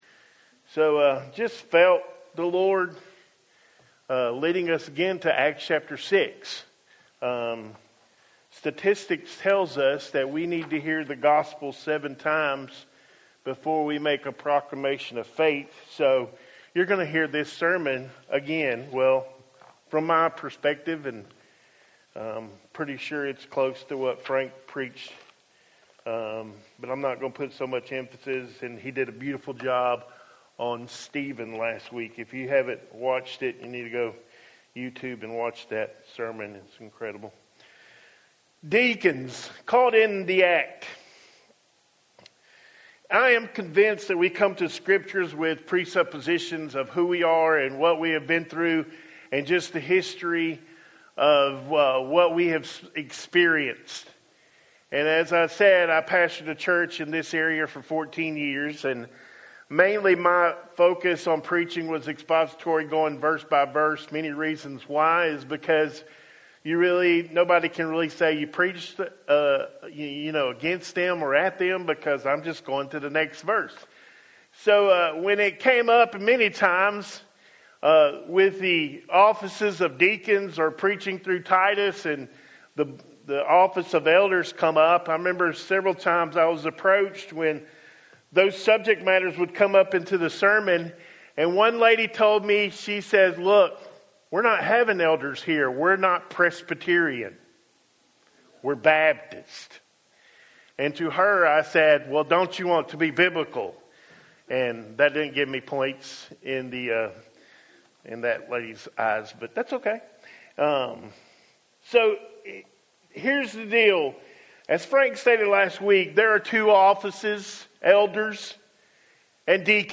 Home › Sermons › Deacons Caught In The Act